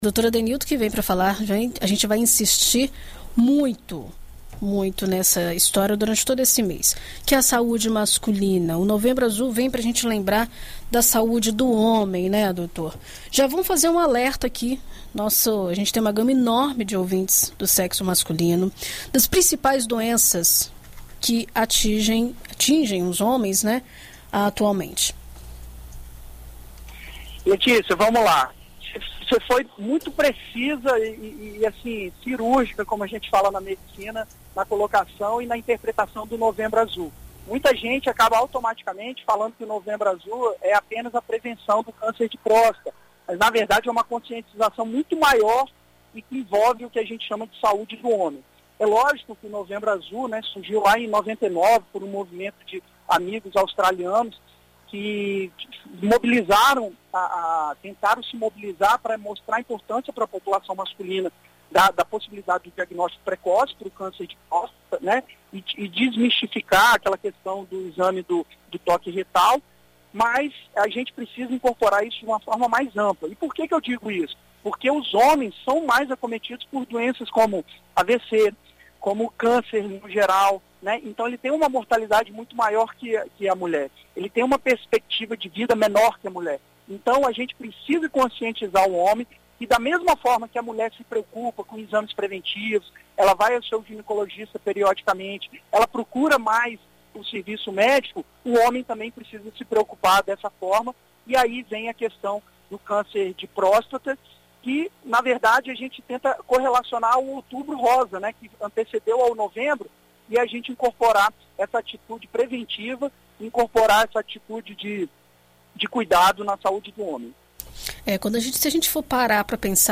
O especialista em mecânica automotiva